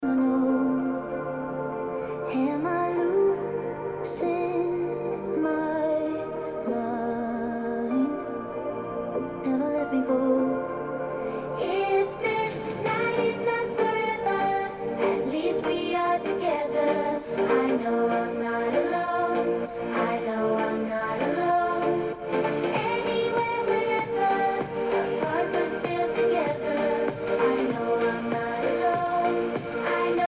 To hear what they sound like, check out the benchmark segment where we have recorded some audio signals.
Without AeroActive Cooler 7
On its own, the phone delivers projected audio with a balanced sound signature towards the user. Its bass is on the lighter side of the spectrum, but it still establishes a balanced sound signature.
Without-Aeroactive-Cooler-7.mp3